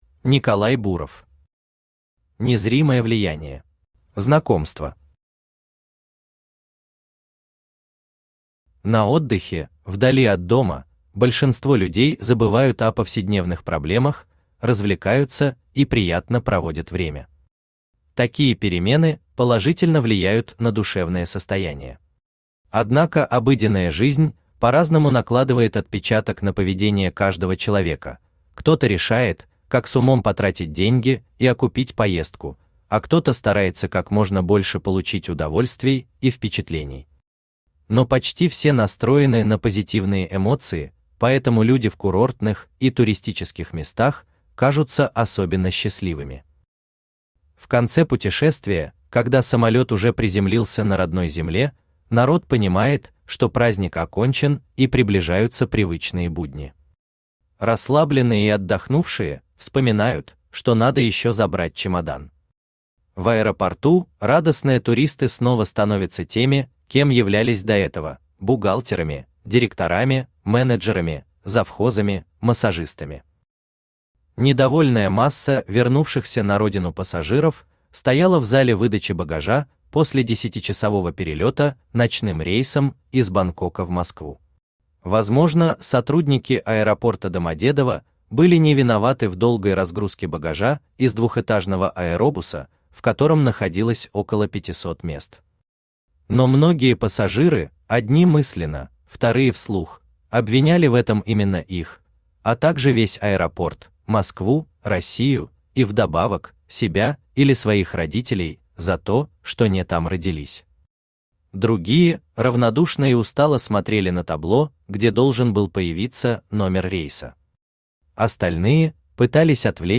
Аудиокнига Незримое влияние | Библиотека аудиокниг
Aудиокнига Незримое влияние Автор Николай Геннадьевич Буров Читает аудиокнигу Авточтец ЛитРес.